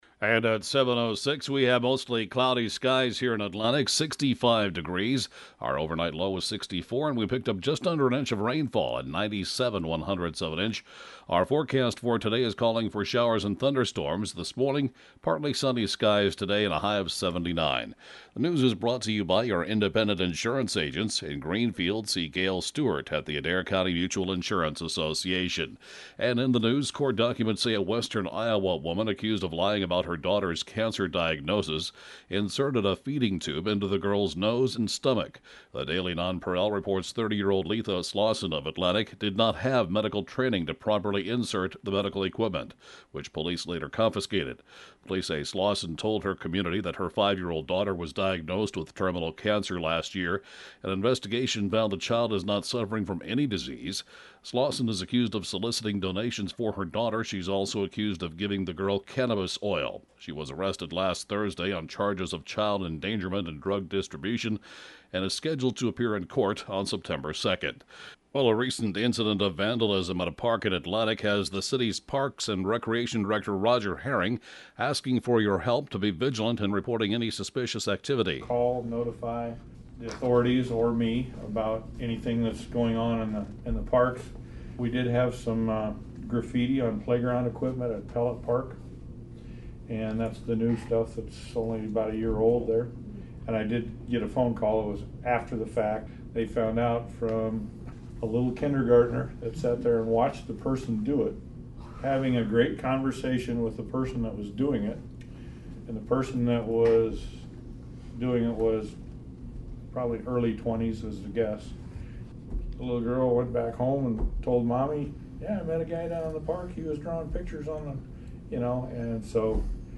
(Podcast) 7:06-a.m. News & funeral report, Tue. – 8/26/14